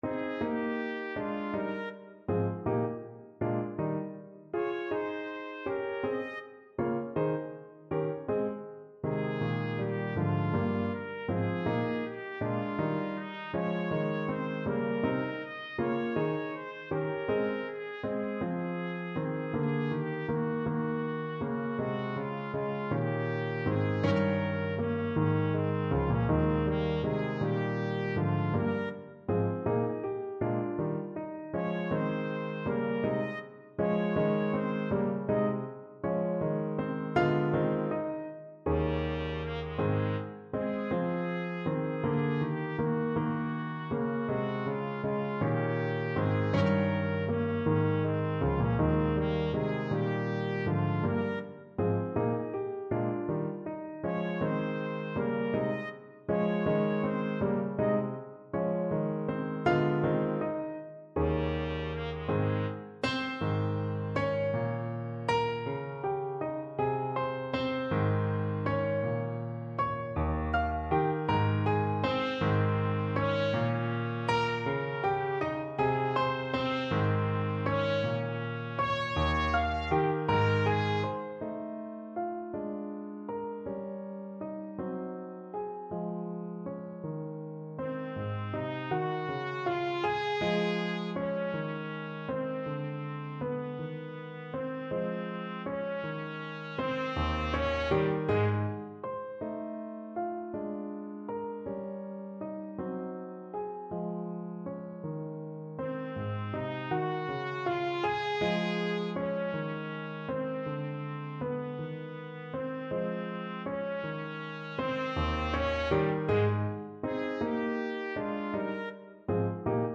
Trumpet version
3/4 (View more 3/4 Music)
Trumpet  (View more Intermediate Trumpet Music)
Classical (View more Classical Trumpet Music)